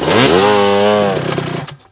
sawstart.wav